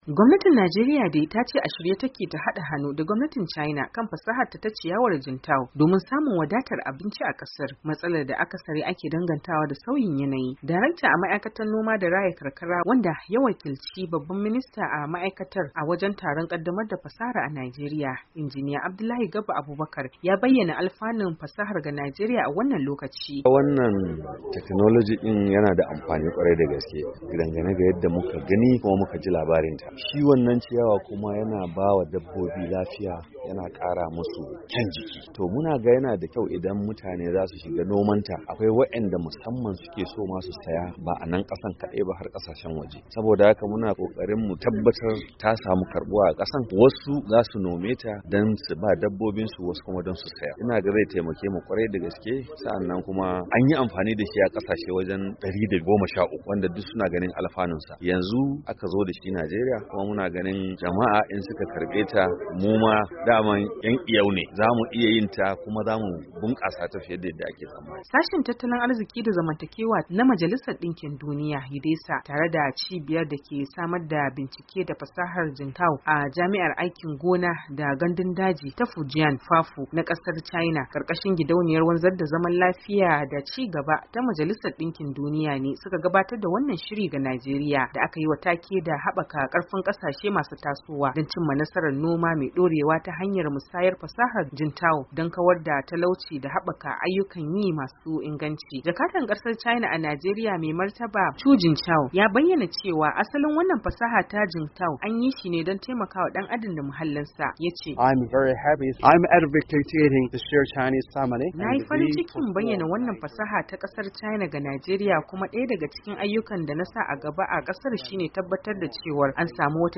Saurari rahoto cikin sauti